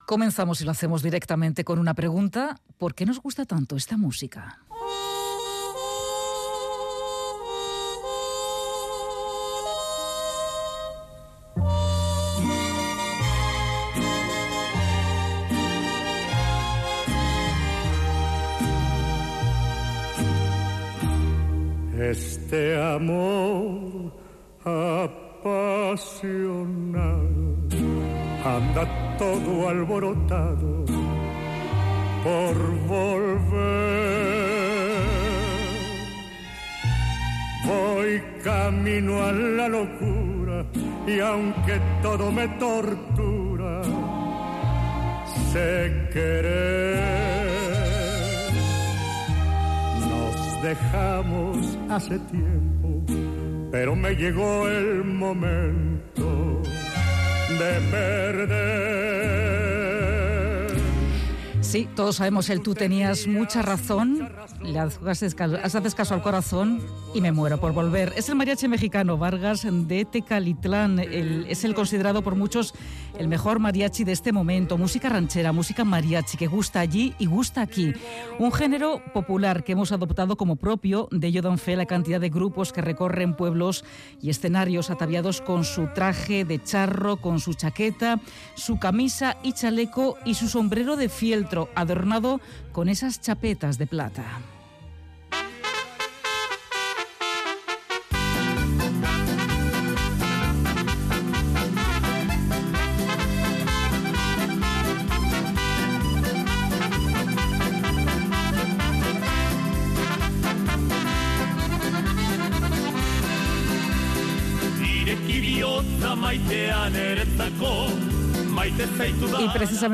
Radio Euskadi ENTREVISTAS ¿Por qué nos gustan tanto las rancheras?